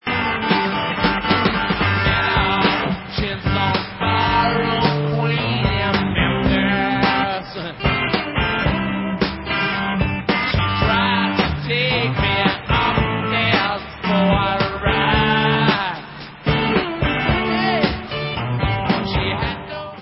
DSD Remastered
Rock